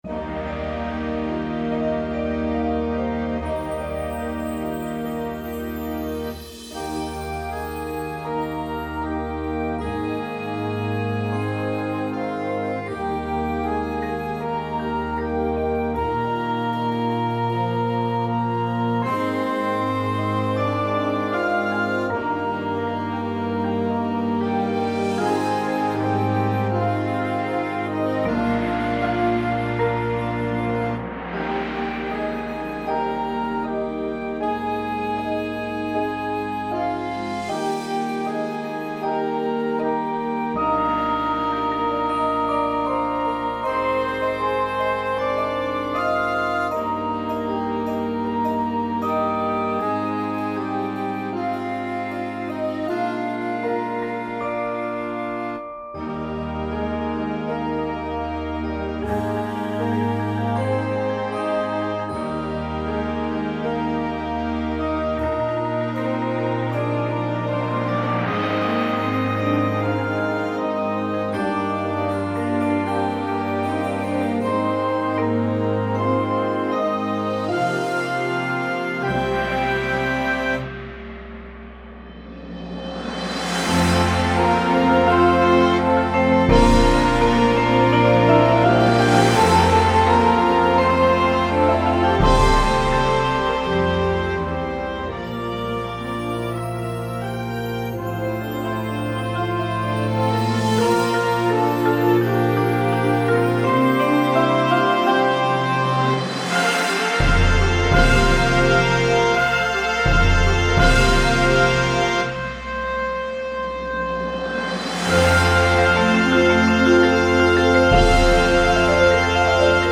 • Flute
• Trombone 1, 2
• Snare Drum